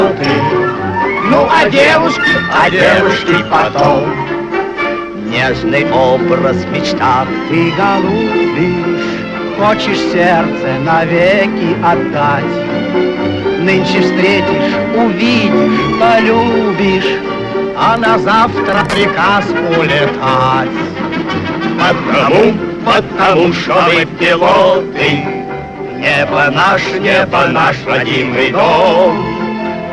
Chanson in Russian